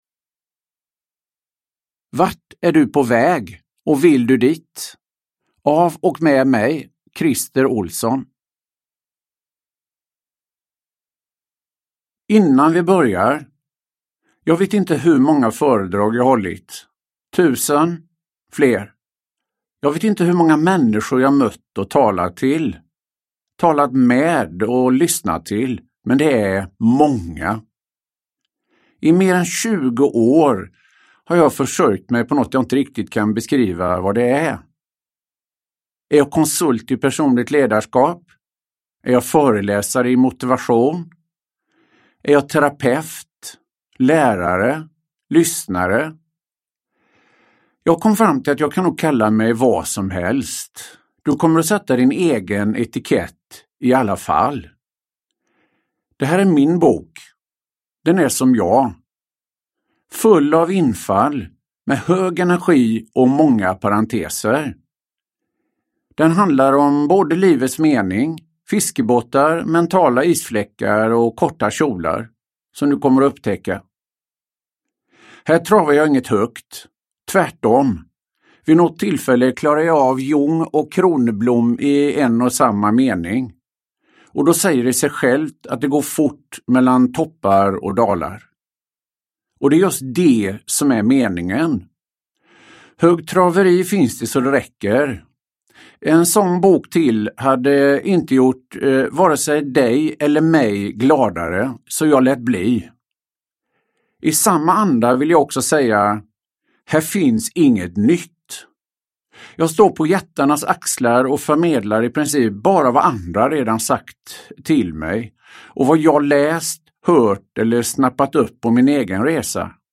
Vart är du på väg och vill du dit? – Ljudbok – Laddas ner